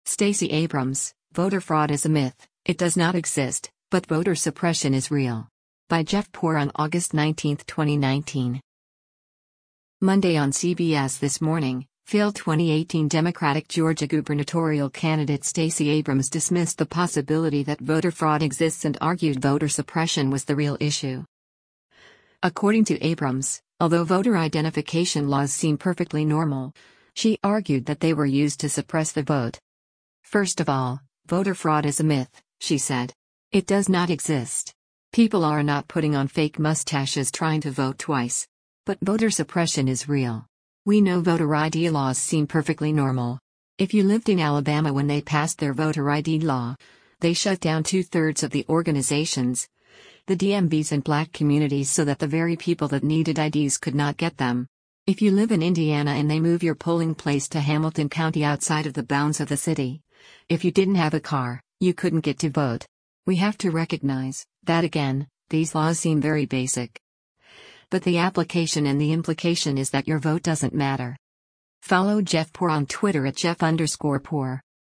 Monday on “CBS This Morning,” failed 2018 Democratic Georgia gubernatorial candidate Stacey Abrams dismissed the possibility that voter fraud exists and argued voter suppression was the real issue.